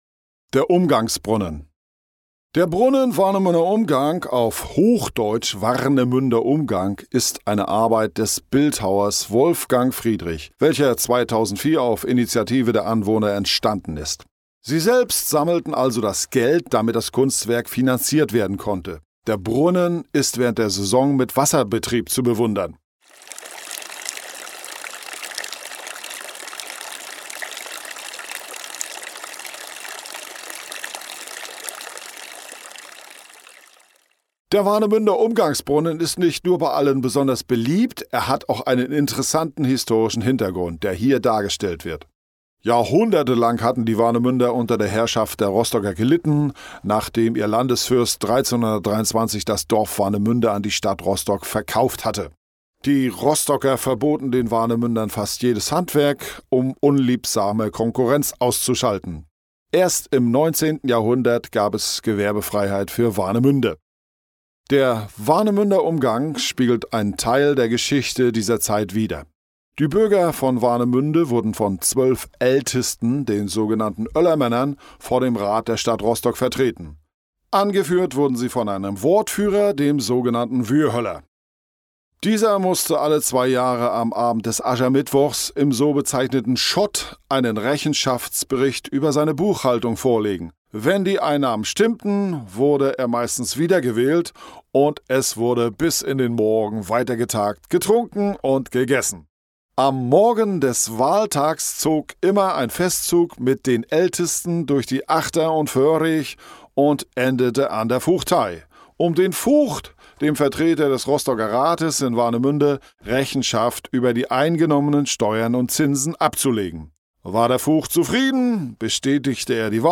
Audioguide Warnemünde - Station 5: Umgangsbrunnen